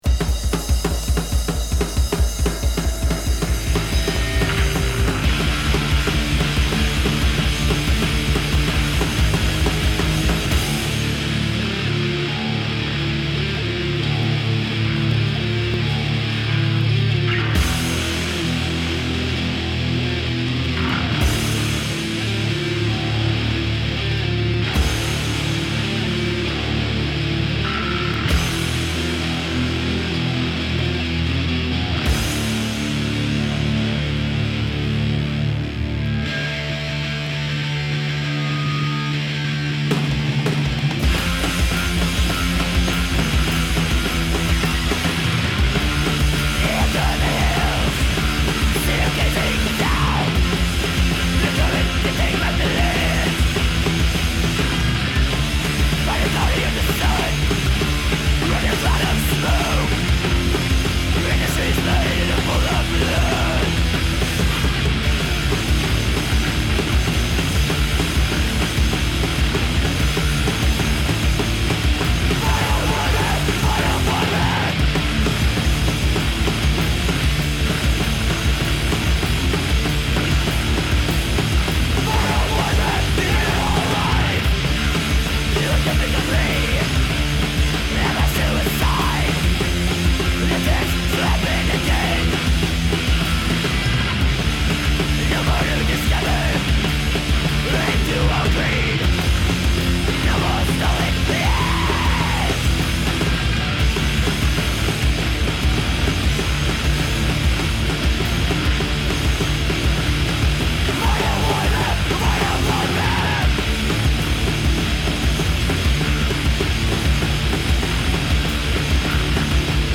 guitar, vocals
bass, vocals
drums